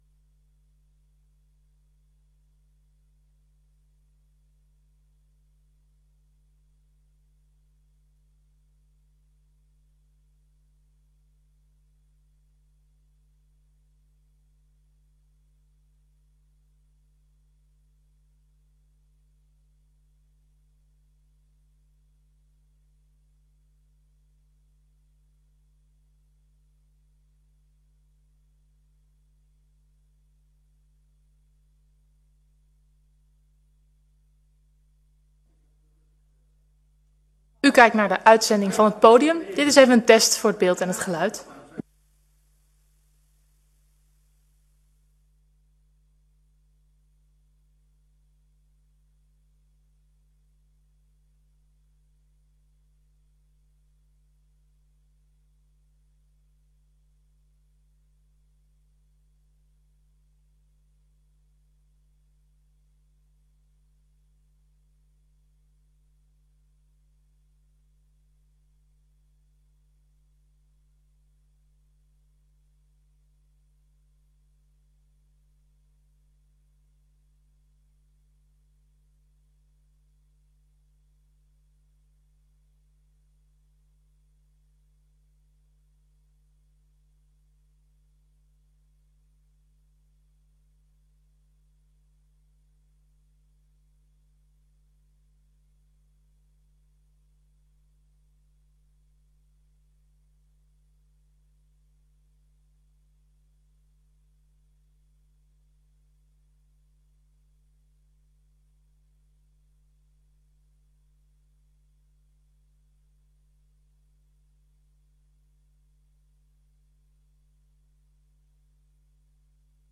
Gemeenteraadsleden mogen in deze tijd ook vragen aan u stellen.